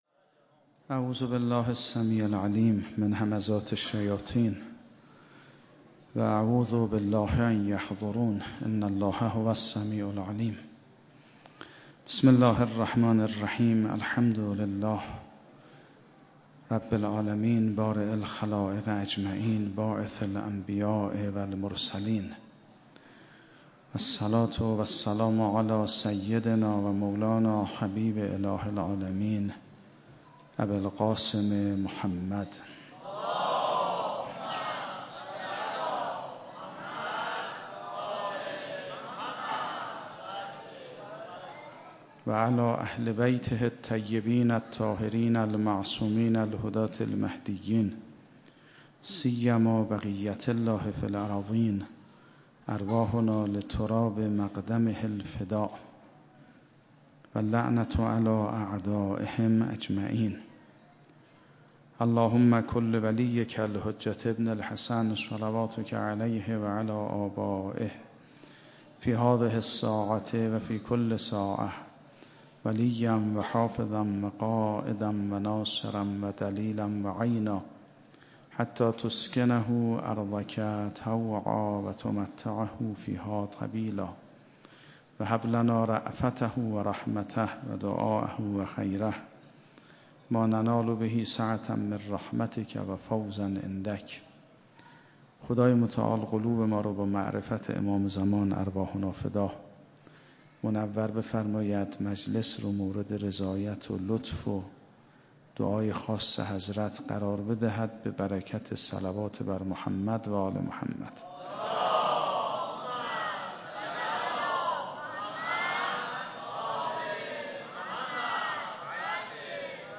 8 خرداد 97 - حسینیه حق شناس - سخنرانی